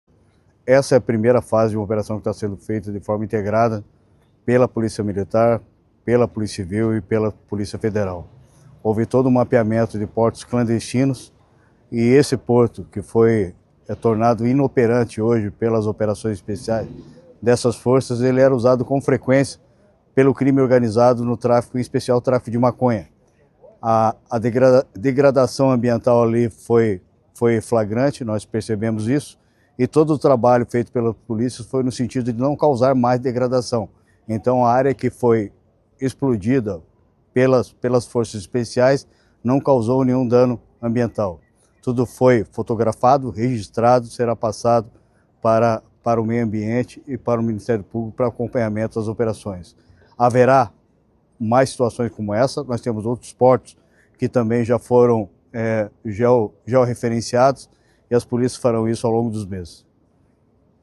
Sonora do secretário Estadual da Segurança Pública, Hudson Teixeira, sobre a detonação de um porto clandestino usado pelo crime organizado, em Guaíra